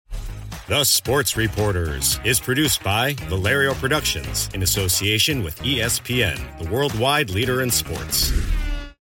Male
Warm, intelligent American male voice for brands that value trust, clarity, and real human connection. I bring an actor's instinct, musicality, and restraint to every read—delivering confident, natural performances that feel grounded, believable, and never synthetic.
Radio / TV Imaging